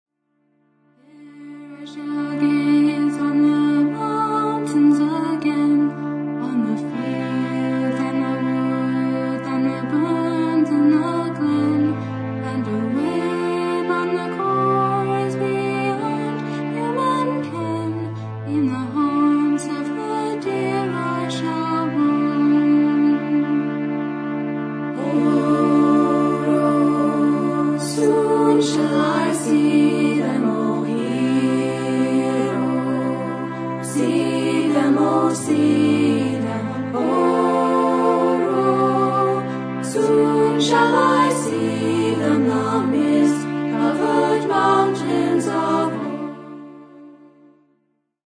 who played Celtic, French-Canadian and original music